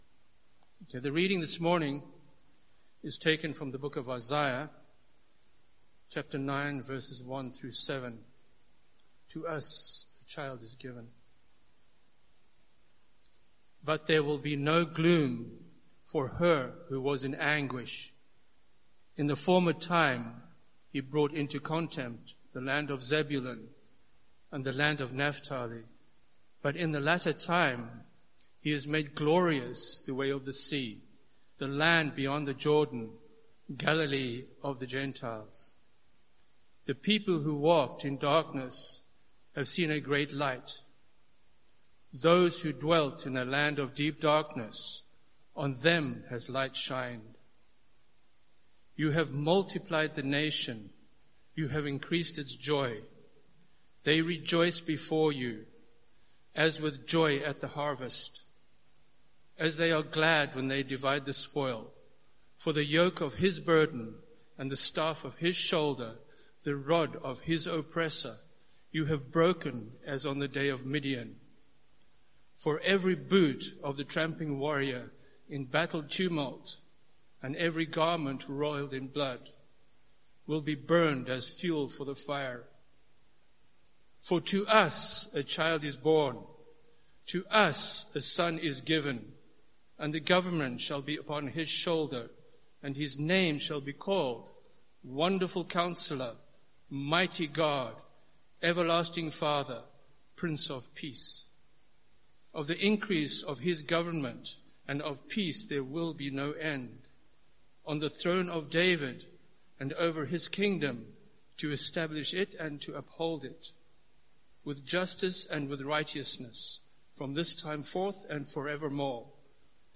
Sermon Series: The Prophecy of Isaiah